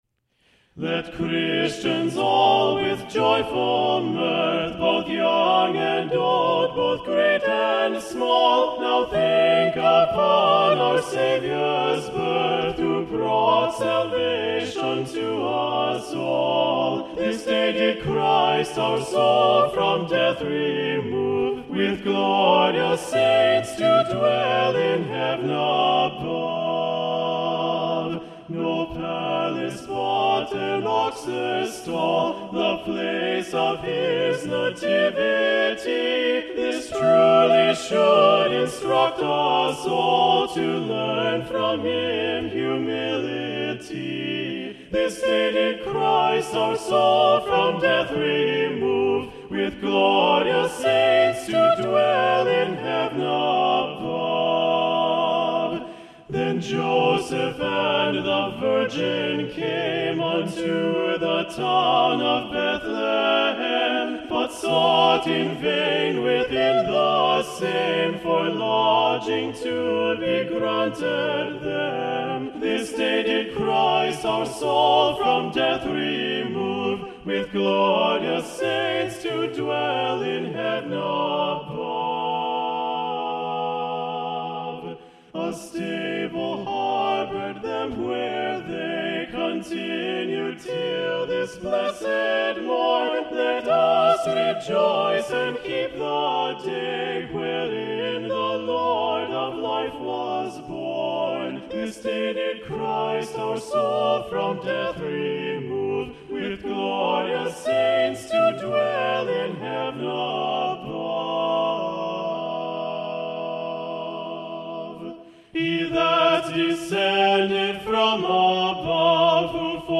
Choral Music — A Cappella